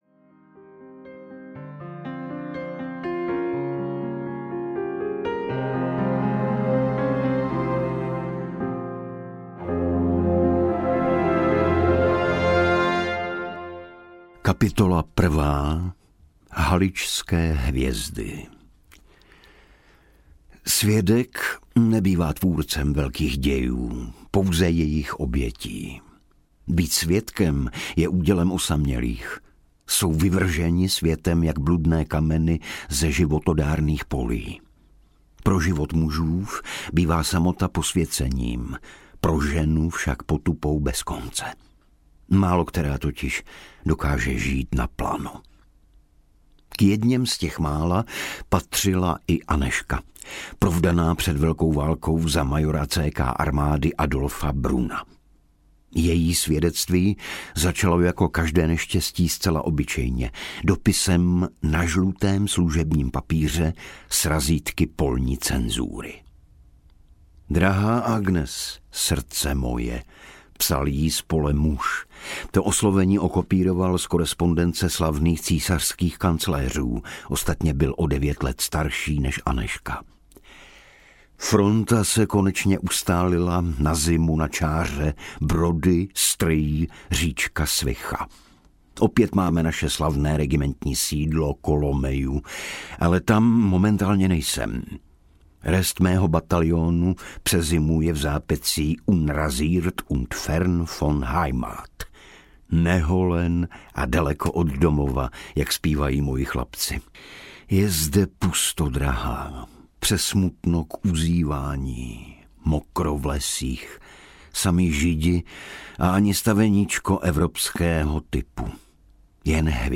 Anděl milosrdenství audiokniha
Ukázka z knihy
• InterpretIgor Bareš